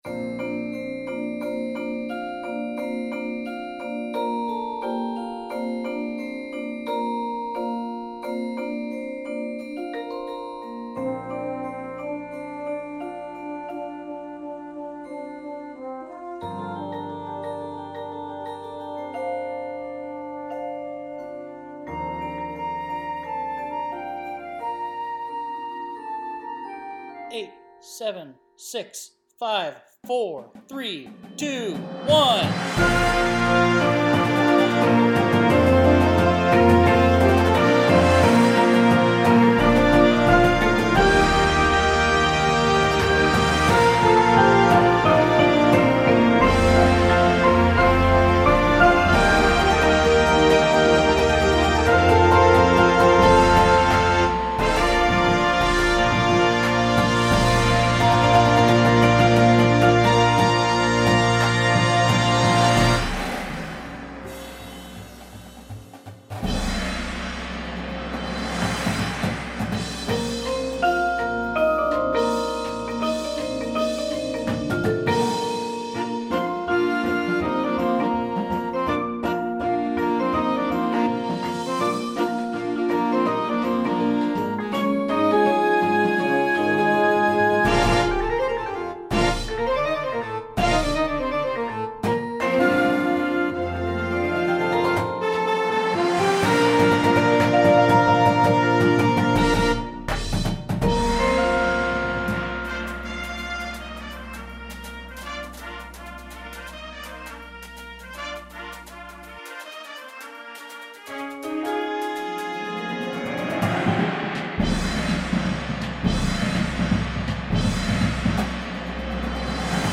Instrumentation:
• Flute
• Clarinet 1, 2
• Alto Sax 1, 2
• Trumpet 1
• Horn in F
• Trombone 1, 2
• Tuba
• Snare Drum
• Bass Drums
• Front Ensemble